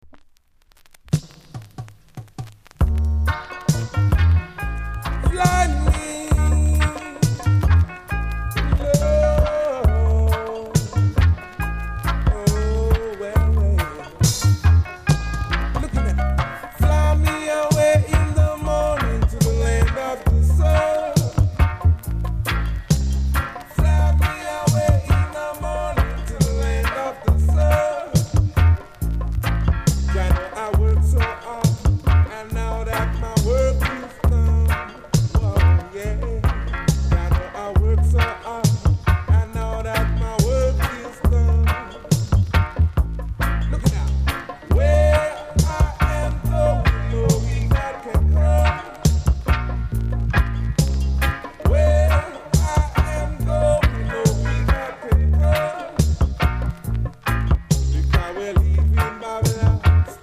※小さなチリノイズが少しあります。
コメント HEAVY UK ROOTS!!RARE!!